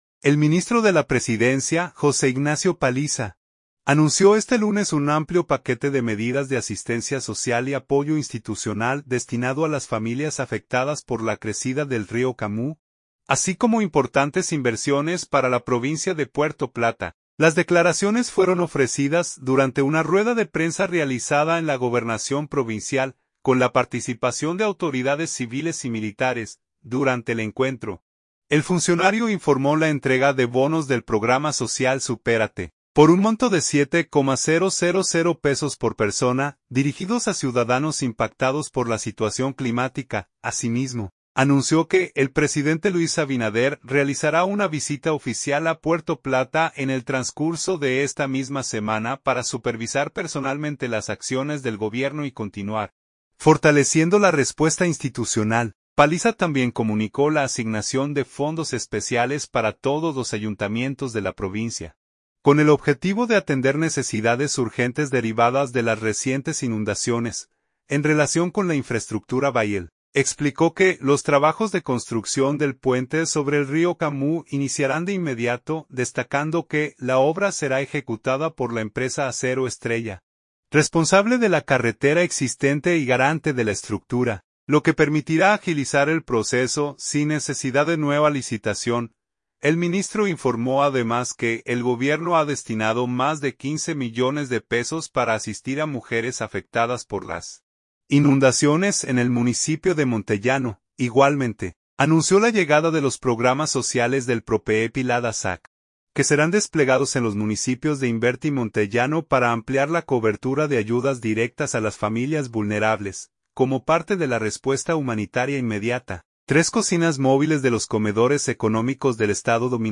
Puerto Plata, República Dominicana. — El ministro de la Presidencia, José Ignacio Paliza, anunció este lunes un amplio paquete de medidas de asistencia social y apoyo institucional destinado a las familias afectadas por la crecida del río Camú, así como importantes inversiones para la provincia de Puerto Plata. Las declaraciones fueron ofrecidas durante una rueda de prensa realizada en la Gobernación Provincial, con la participación de autoridades civiles y militares.